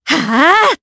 Yanne-Vox_Casting3_jp.wav